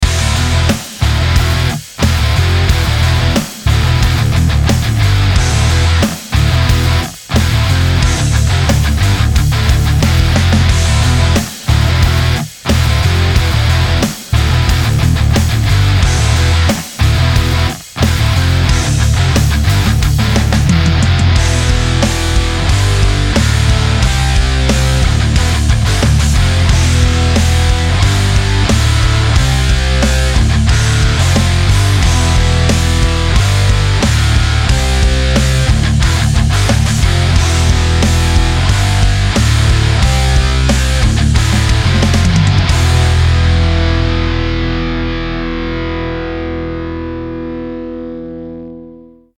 The guitars have been recorded twice (one for each side) and then sent to a bus with an instance of the C165a VCA compressor on it.
In the end, this will introduce some nice saturation and give the sound a rich tone.
The changes aren’t dramatic, but still the guitars sound a little more ‘together’, with more weight, and also some more movement!
Compression_Saturation_Fooler_Wet.mp3